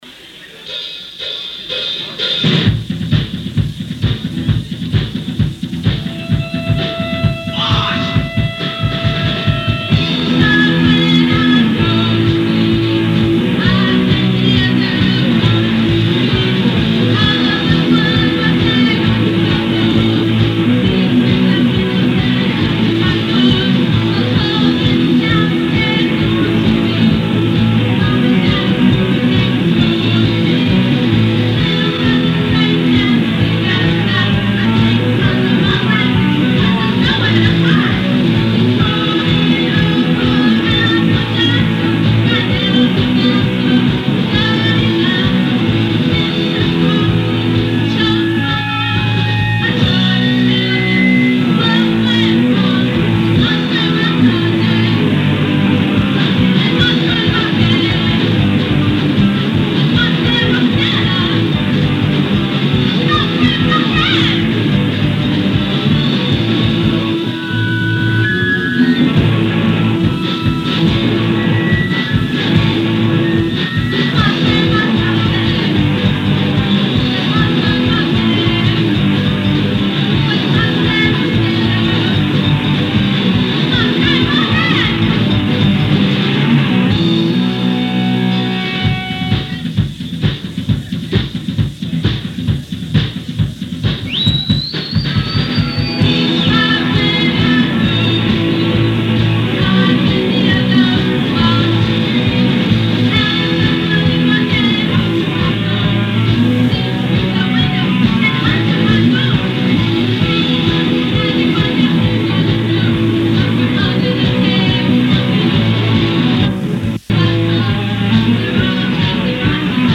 @ Caddyshack, Catasaque PA 1-19-86